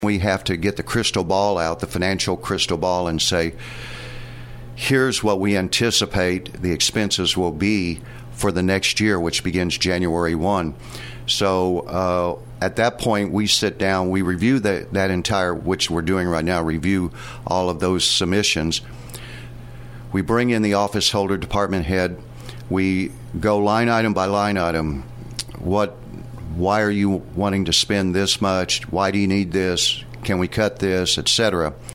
Louie Sieberlich, St. Francois County Auditor, sat down with KFMO to explain his office's work and what they do on a day-to-day basis. He says part of building the next year's budget is guesswork, and meeting with department heads to seek clarification on their plans for spending.